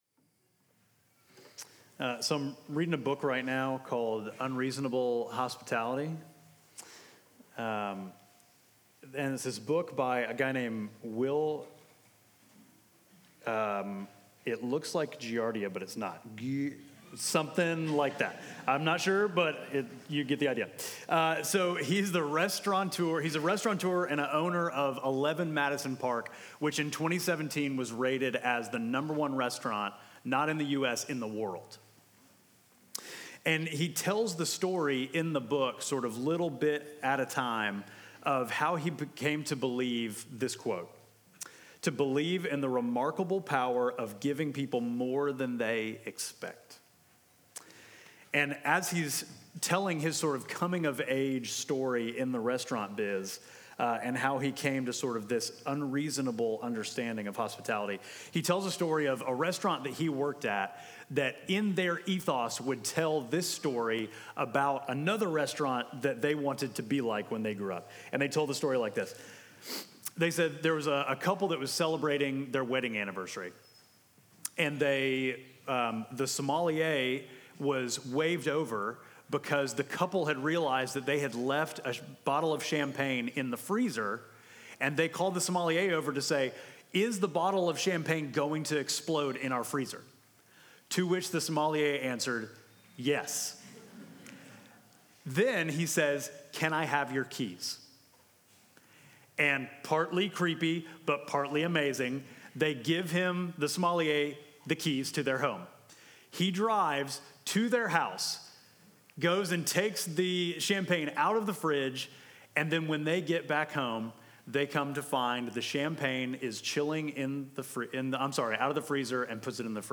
Midtown Fellowship Crieve Hall Sermons Feasting By Faith Jul 14 2024 | 00:29:57 1x 00:00 / 00:29:57 Subscribe Share Apple Podcasts Spotify Overcast RSS Feed Share Link Embed